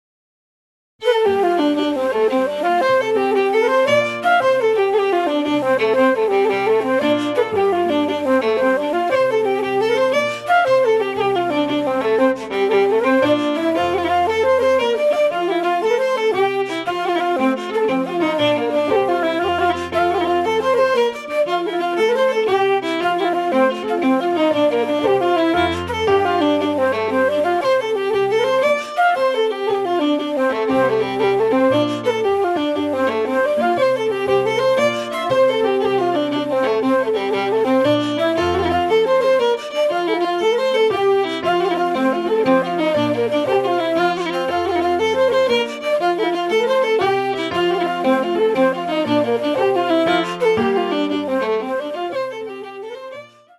Slip Jigs 03:47